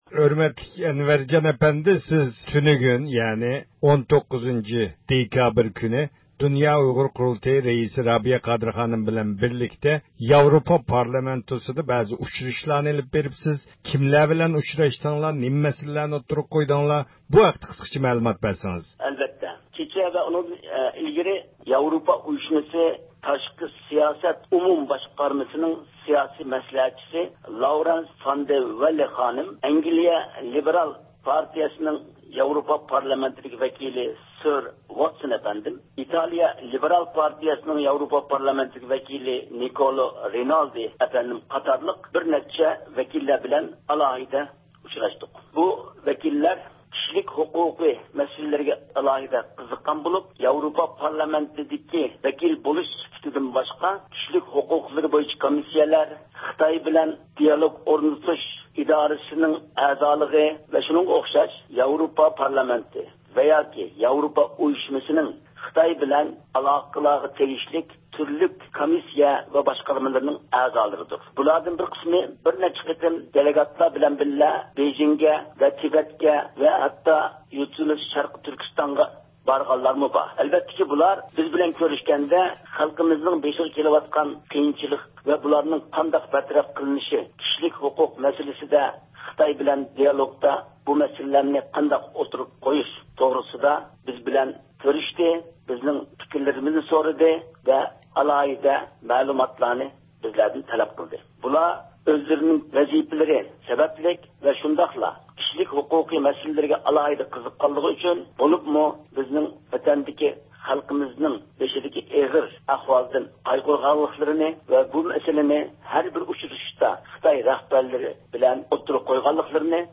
سۆھبەت ئېلىپ باردۇق.